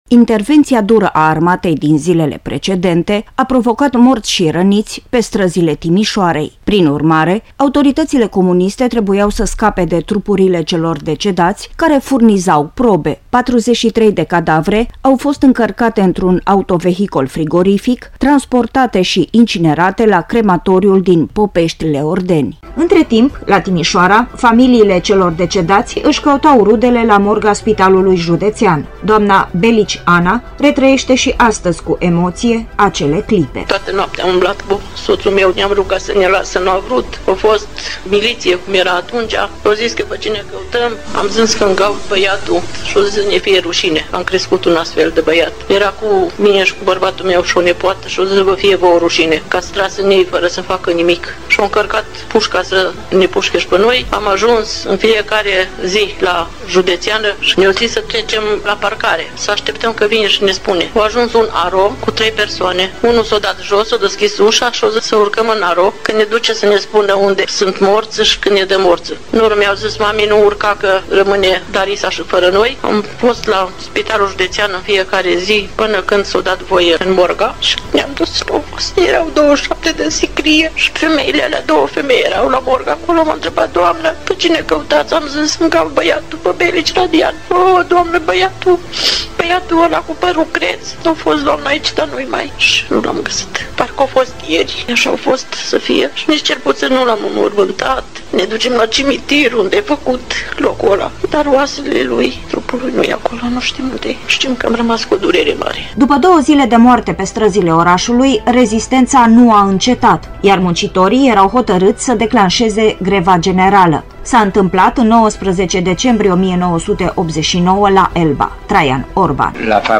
Declaraţii şi rememorări ale participanţilor direcţi la evenimentele acelor zile